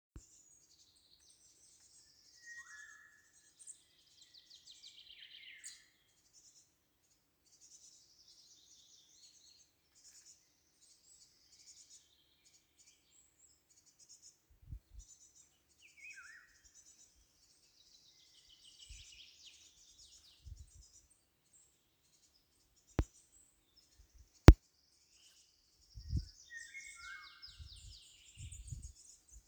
Птицы -> Иволговые ->
иволга, Oriolus oriolus
ПримечанияRedzēti 2 putni mežā pie ezera.